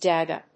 /ˈdʌxə(米国英語)/